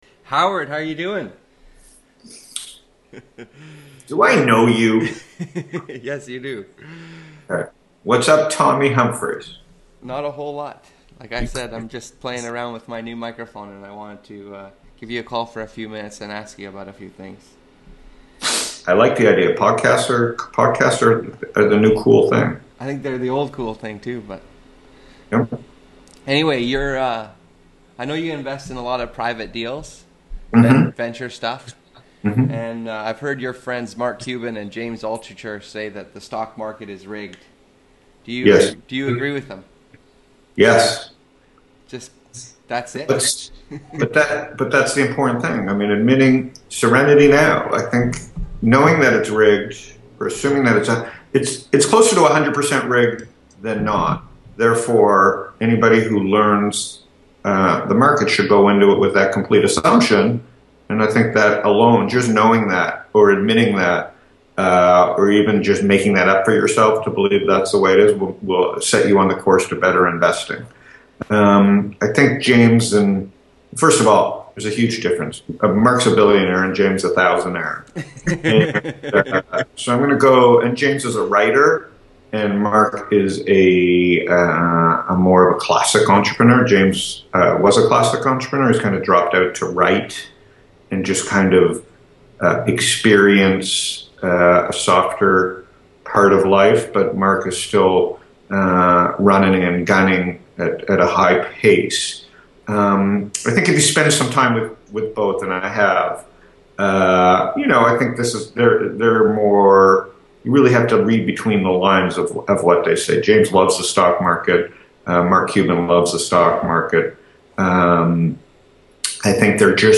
A 20 minute call